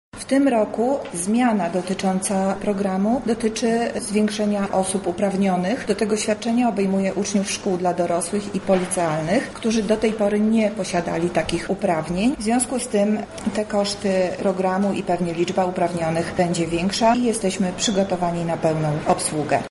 Jakie są najnowsze zmiany w programie Dobry Start, tłumaczy zastępca prezydenta ds. społecznych Monika Lipińska: